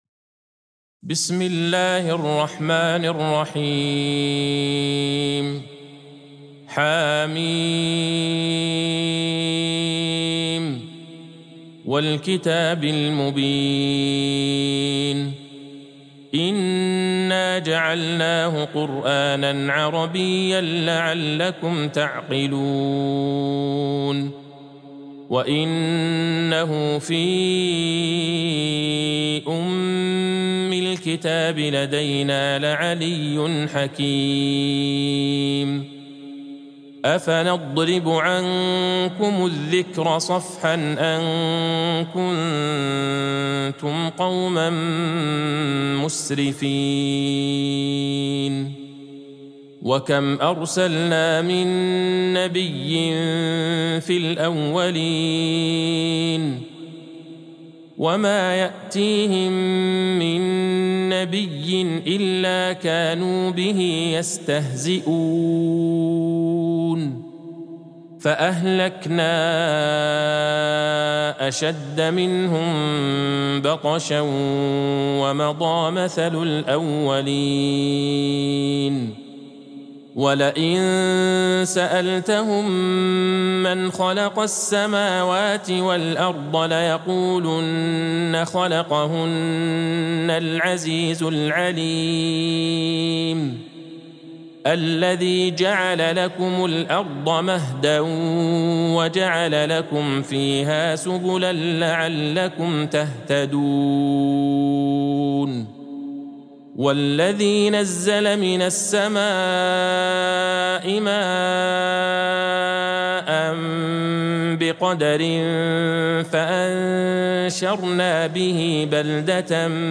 سورة الزخرف Surat Az-Zukhruf | مصحف المقارئ القرآنية > الختمة المرتلة ( مصحف المقارئ القرآنية) للشيخ عبدالله البعيجان > المصحف - تلاوات الحرمين